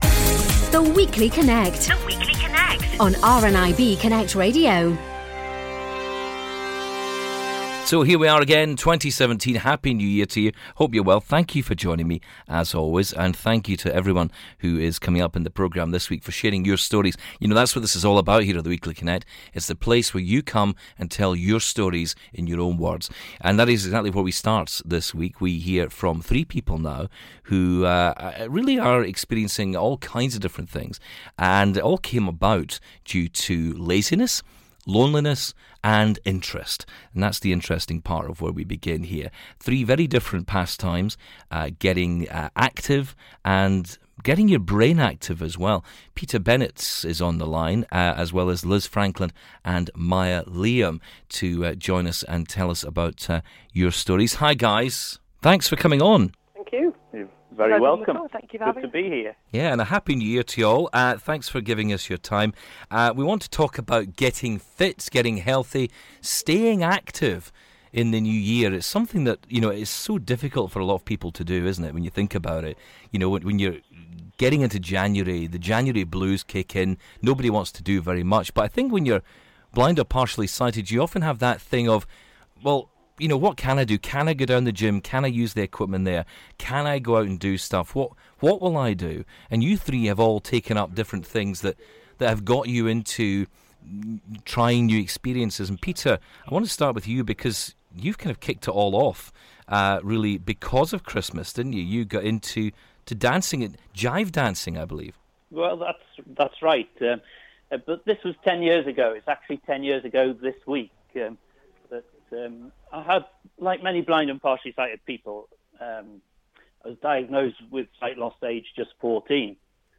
Discussion: New Year's Resolutions